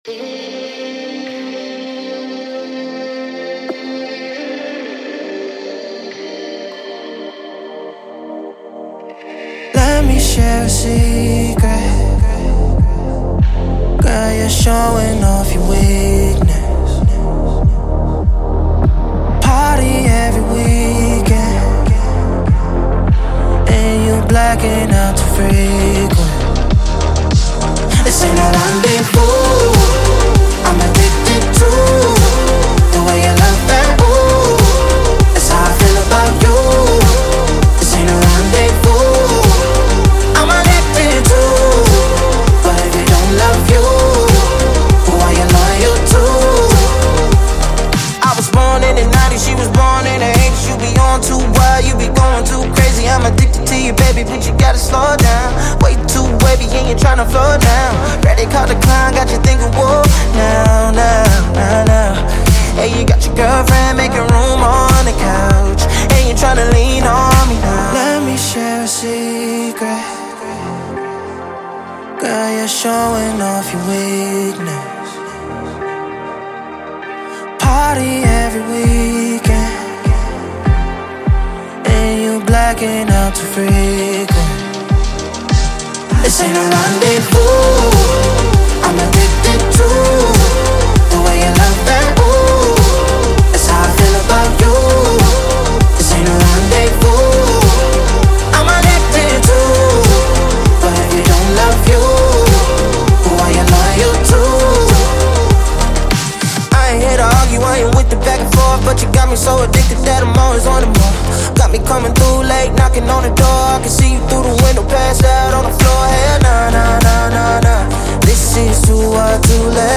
это энергичная трек в жанре R&B и поп
Кристально чистый голос
делает трек динамичным и танцевальным